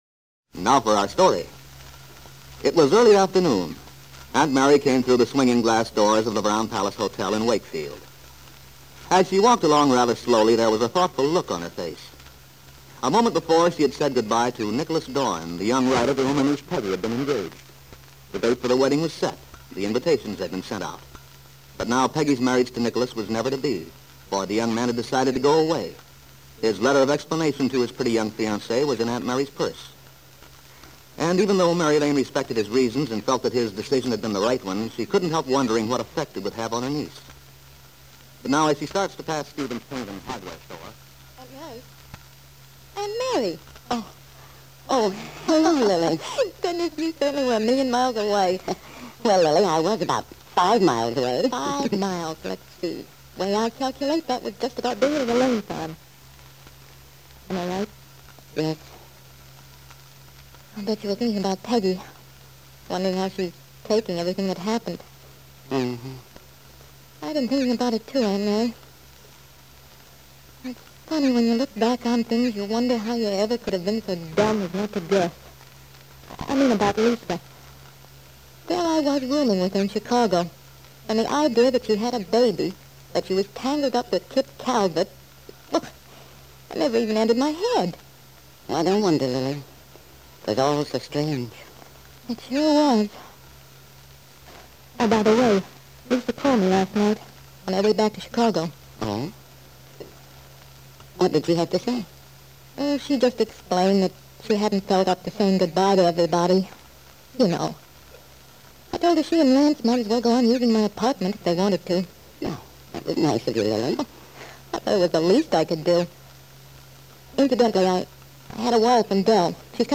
Aunt Mary was a soap opera that follows a story line which appears to have been broadcast in 1945 and early 1946. The plot features a love triangle and involves a young woman in a failing marriage who pays an extended visit to "friends" in Los Angeles to conceal a dark secret from her father and others back in Wakefield.